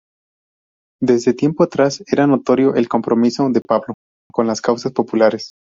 Pronounced as (IPA) /ˈpablo/